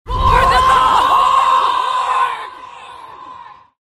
голосовые
крик
из игры